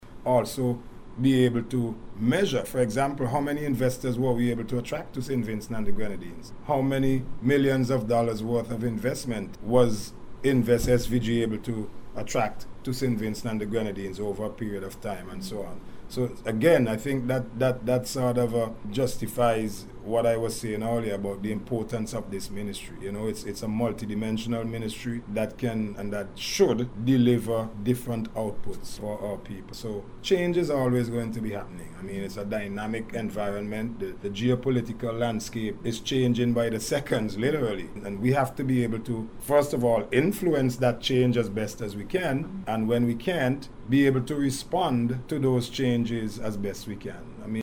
In a recent interview, Minister Bramble noted that measuring the success of investment initiatives is key, highlighting the millions of dollars in foreign capital attracted over time.